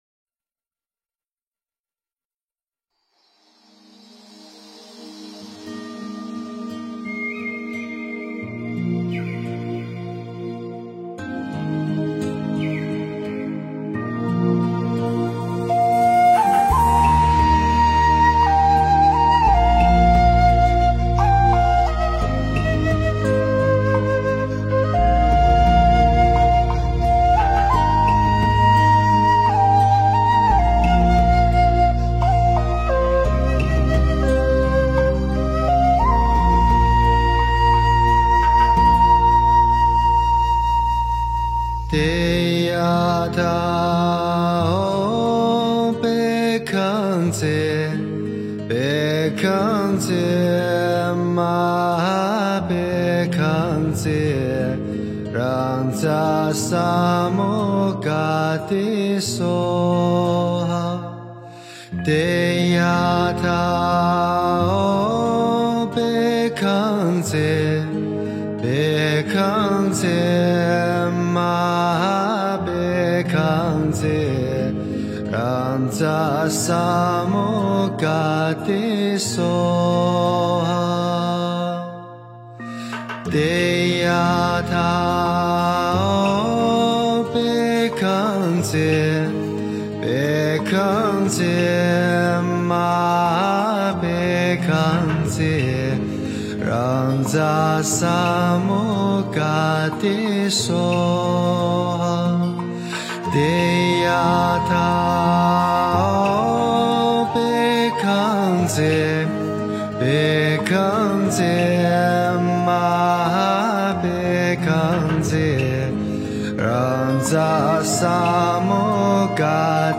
诵经
佛音 诵经 佛教音乐 返回列表 上一篇： 白度母心咒 下一篇： 般若波罗蜜多心经 相关文章 地藏十轮经08--梦参法师 地藏十轮经08--梦参法师...